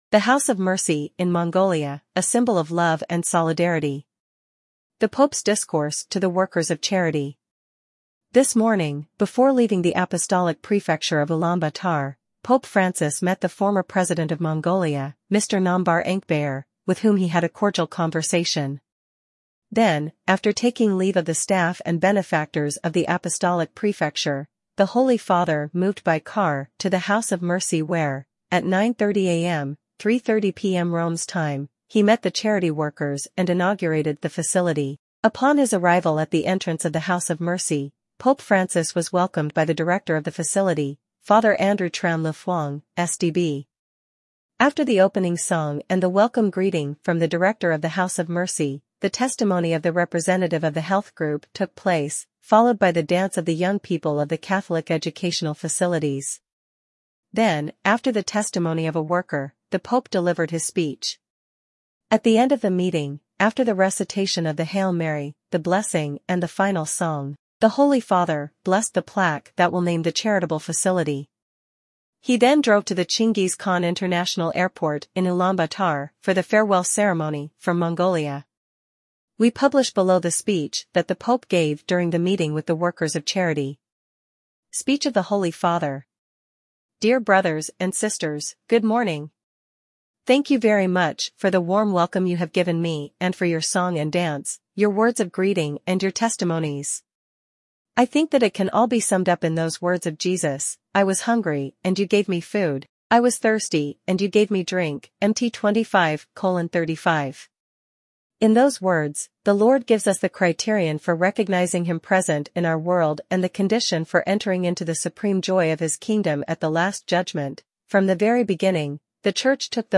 Speech of the Holy Father